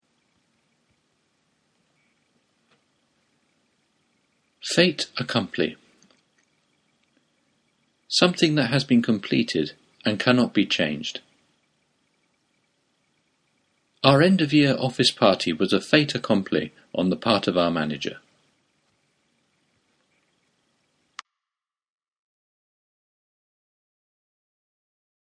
発音は、フランス語風にt と a をつなげて、「タ」のようになります。
ネイティブによる発音は下記のリンクをクリックしてください。